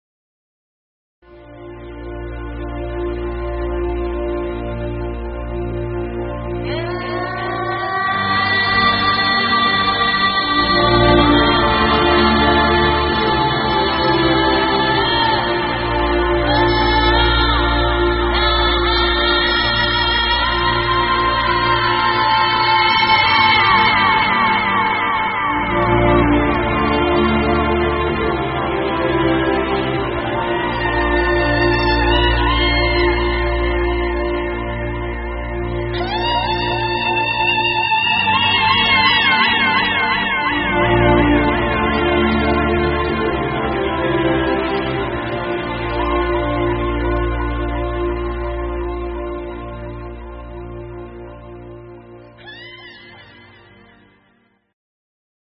it is actually a mix of a classical track